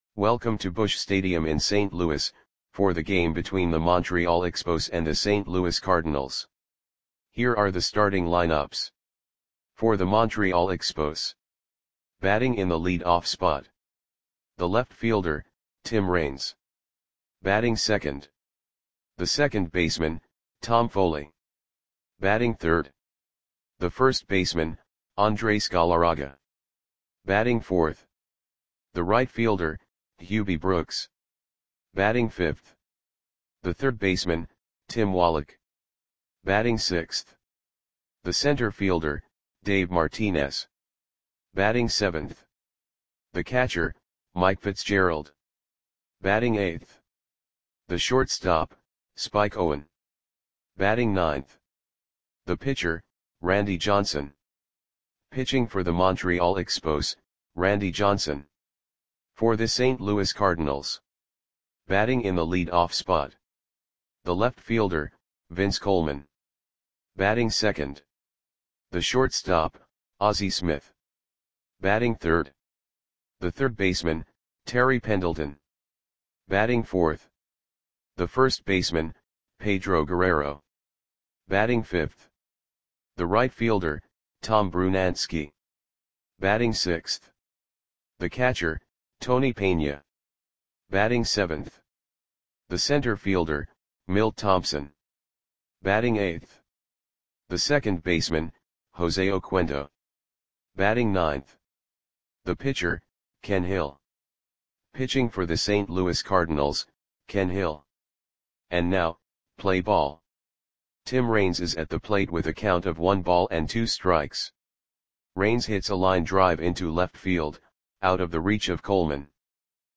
Audio Play-by-Play for St. Louis Cardinals on April 20, 1989
Click the button below to listen to the audio play-by-play.